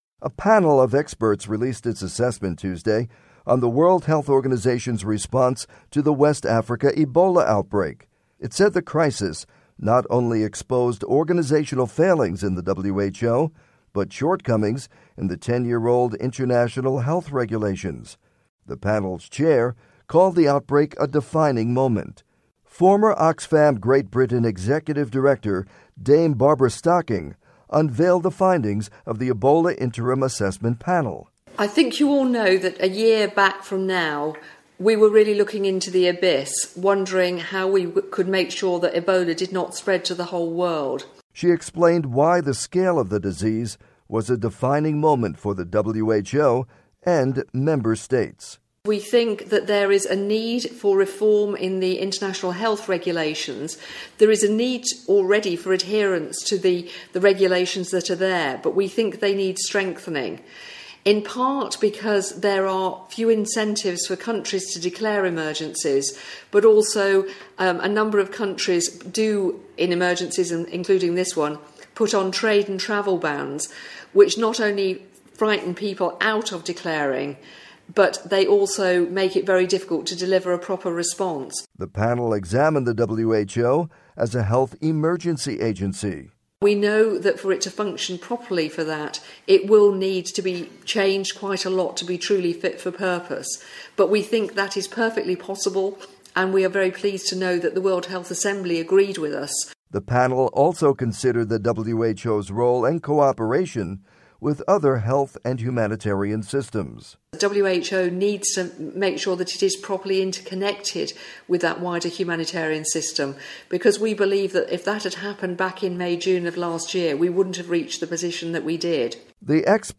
report on Ebola interim assessment